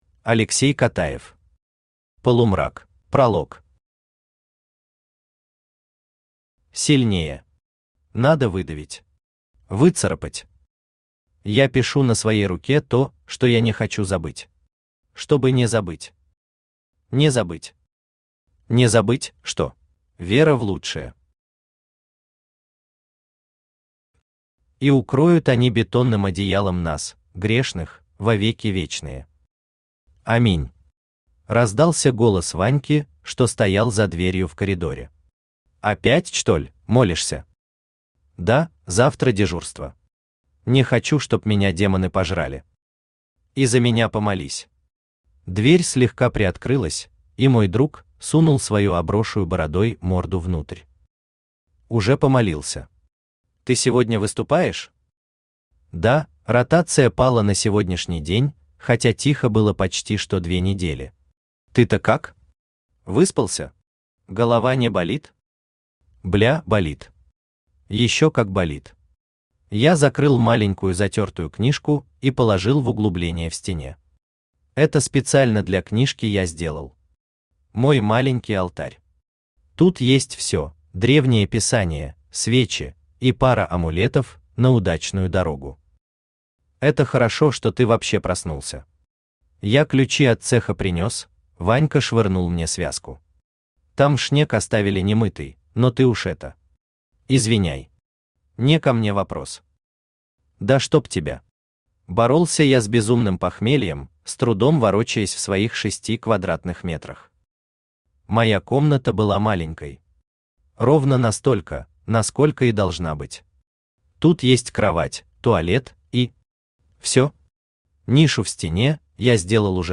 Aудиокнига Полумрак Автор Алексей Котаев Читает аудиокнигу Авточтец ЛитРес.